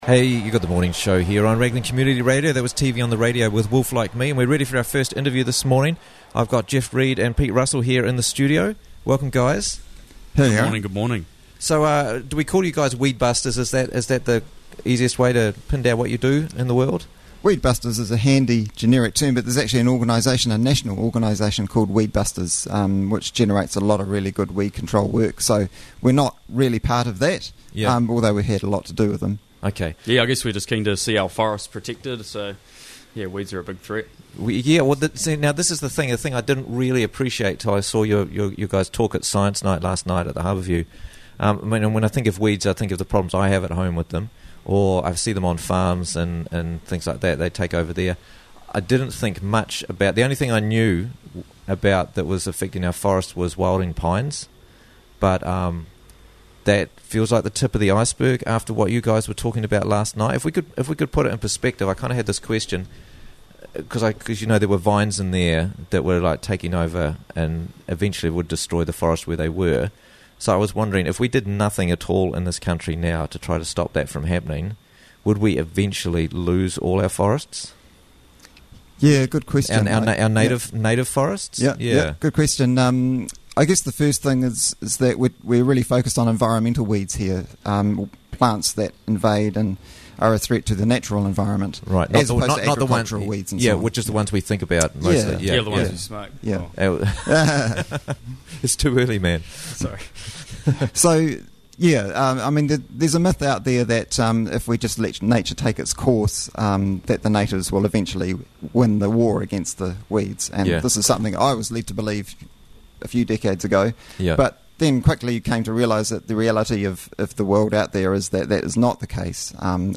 Is A National Weed Crisis On The Way - Interviews from the Raglan Morning Show